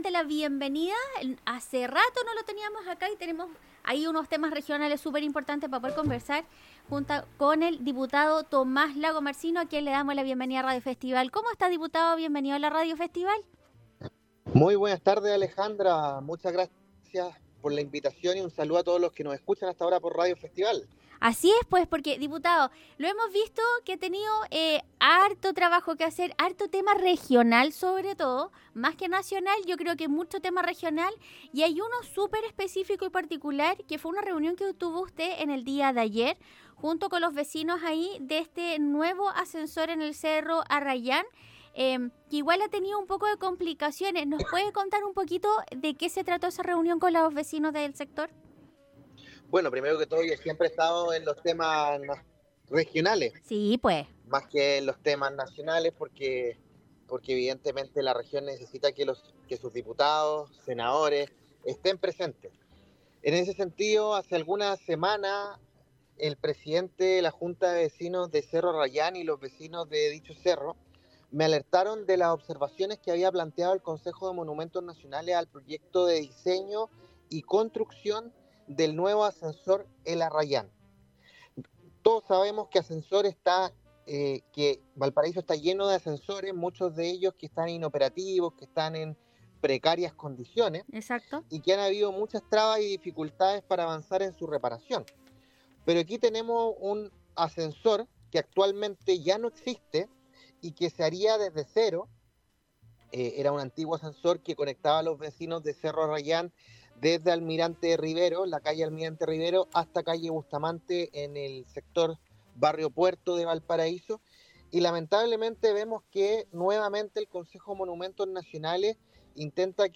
Esta jornada en los estudios de Radio Festival el Diputado analizó el proyecto de nuevo ascensor en el cerro Arrayán en Valparaíso, que se encuentra paralizado